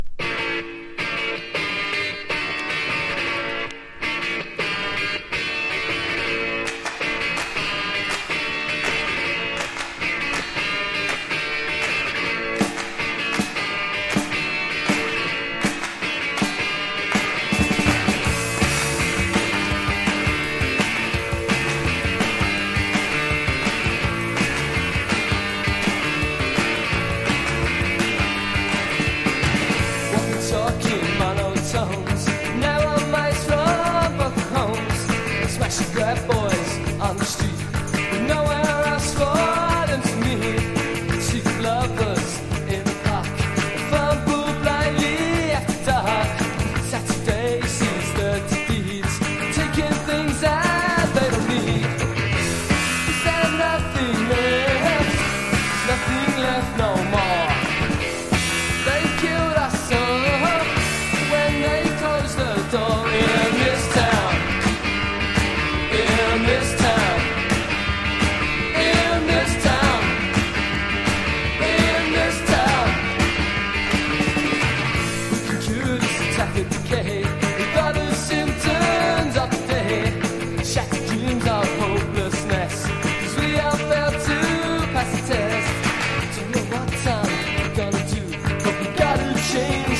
# 初期PUNK / POWER POP